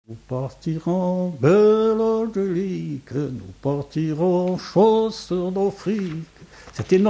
Refrain de conscrits
Chants brefs - Conscription
Pièce musicale inédite